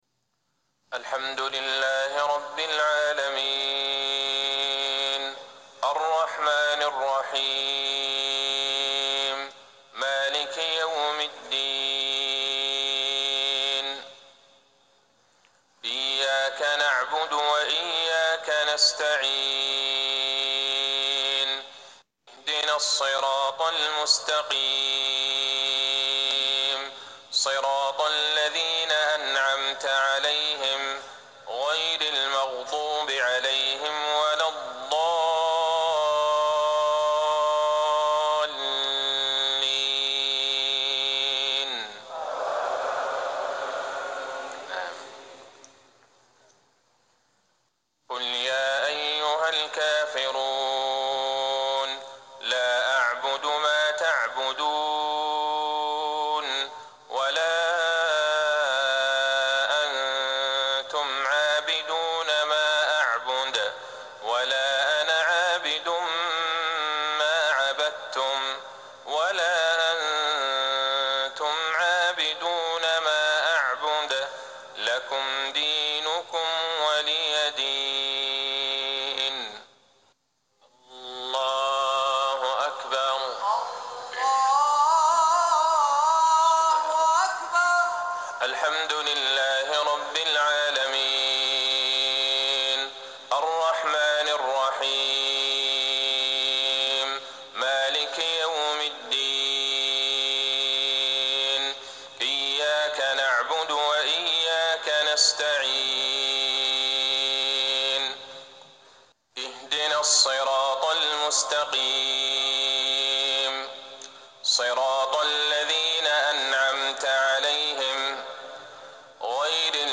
صلاة المغرب 2-6-1440هـ سورتي الكافرون والإخلاص | Maghrib 7-2-2019 prayer from Surat Al-kafirun and Al-Ikhlas > 1440 🕌 > الفروض - تلاوات الحرمين